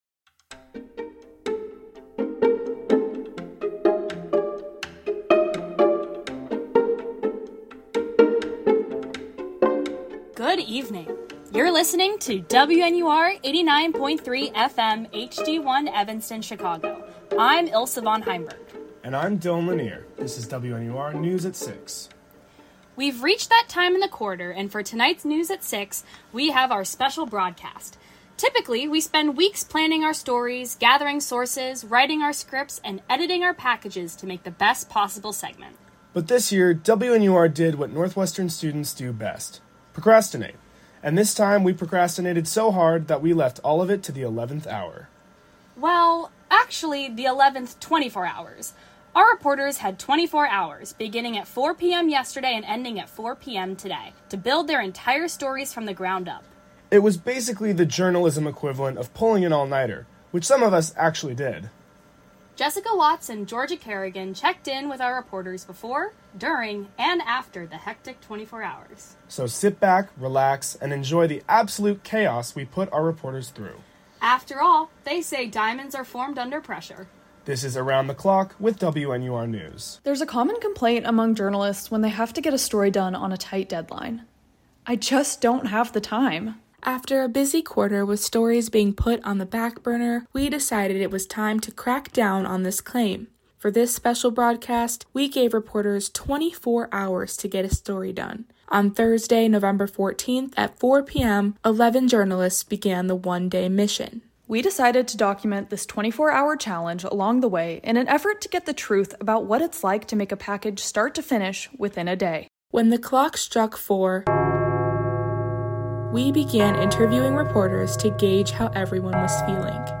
For our Fall Quarter 2024 Special Broadcast, we decided to do things a bit differently, instead of spending weeks to plan our stories, this time all of the